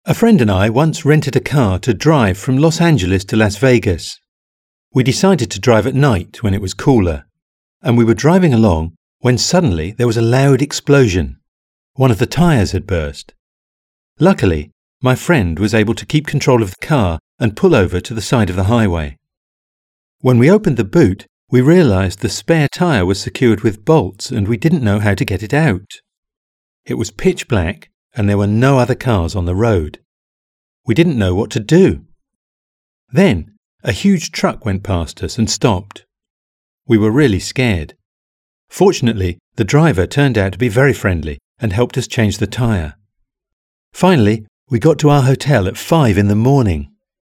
Listen to someone describing a road trip to Las Vegas.